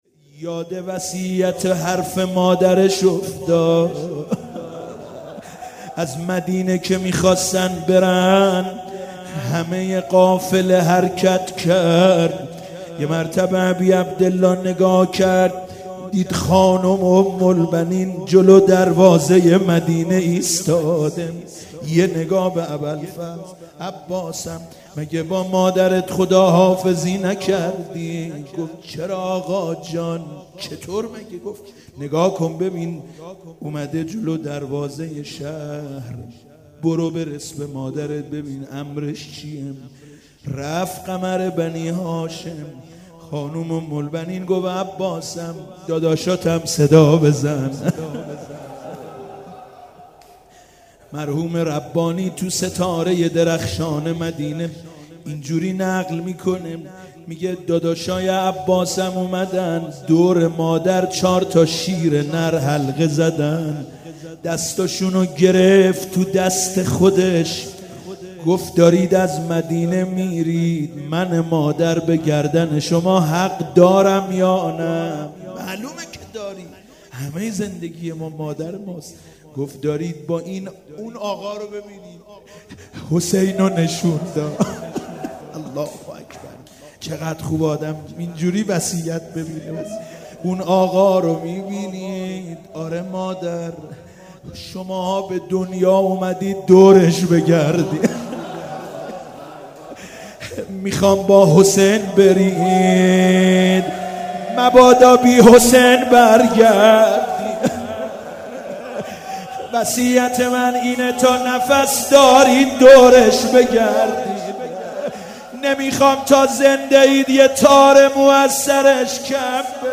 شهادت امام کاظم (ع)97 - بیت العباس (ع) - روضه - برای آینه بودم که انتخاب شدم